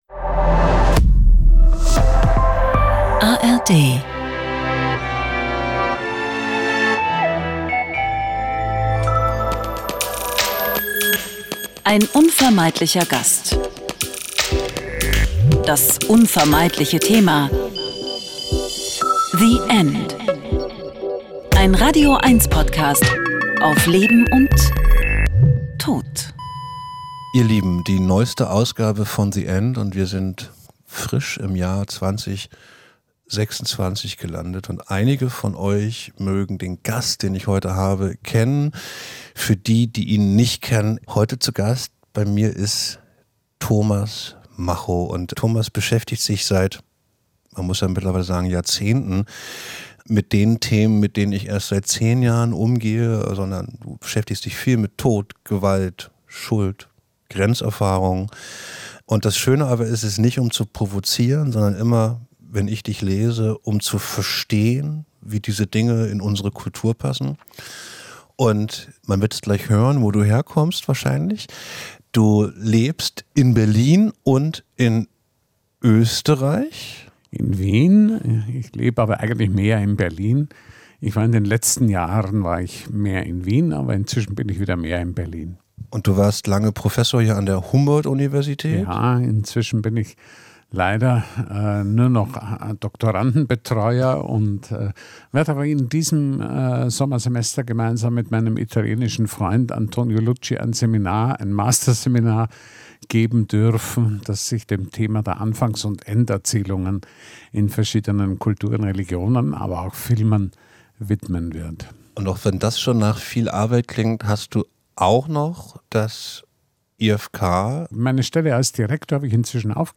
Ein Gespräch über die neue Sichtbarkeit des Todes, die Vielfalt des künstlerischen Umgangs damit und unser Verhältnis zum Suizid.